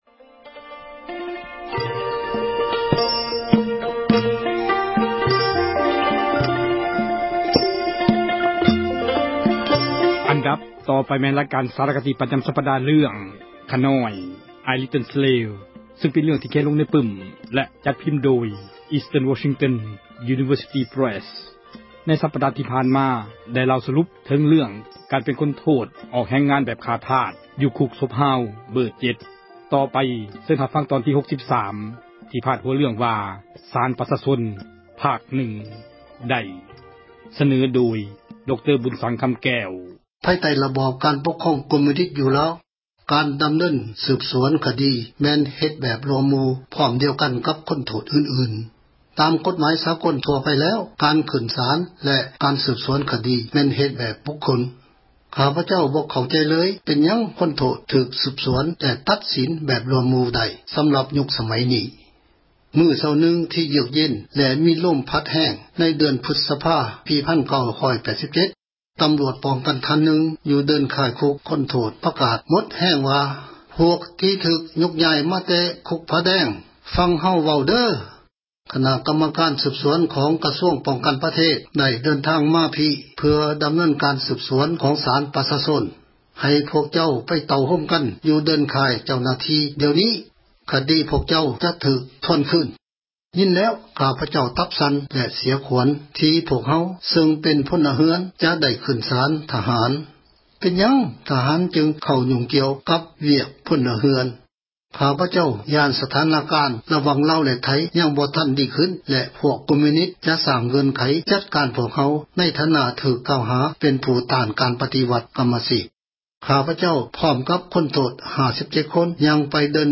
ສາຣະຄະດີ ເຣື່ອງ ”ຂ້ານ້ອຍ"